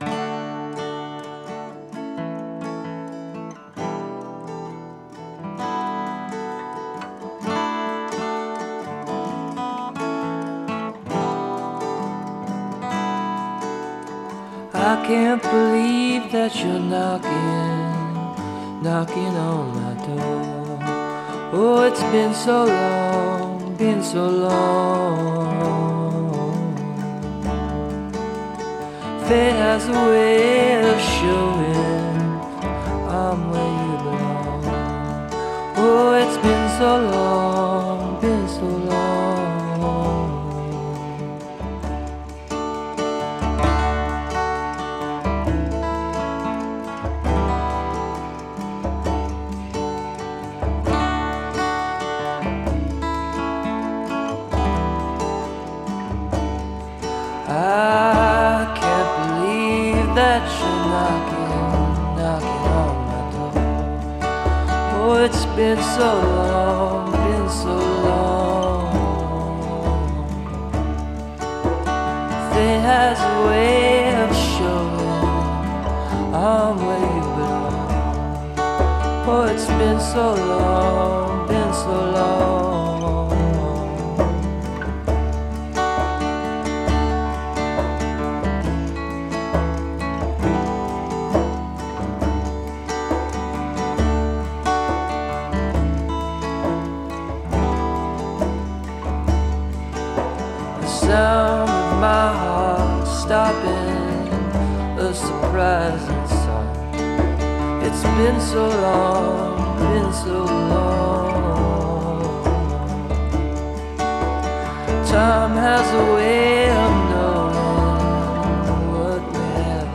Alternative Folk Psychedelic Rock
Enjoy this live mix from 2005